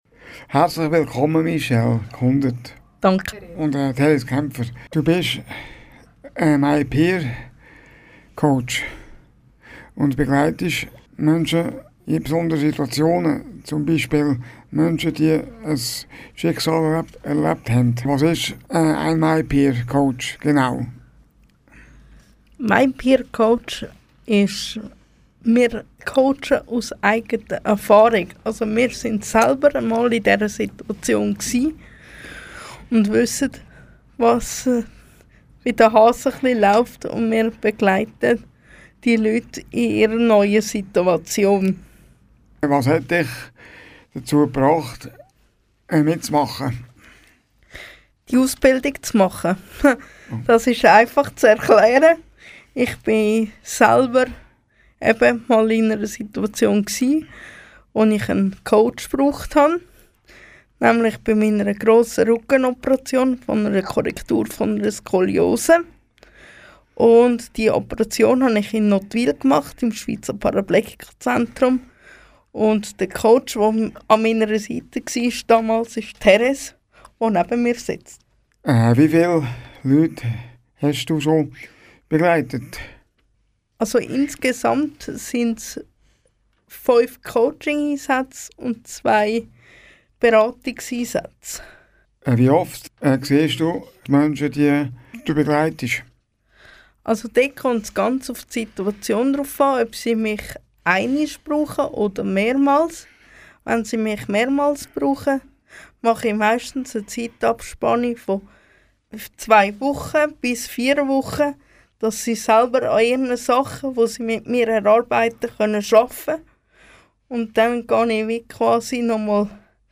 Happy Radio Kanal K – Interview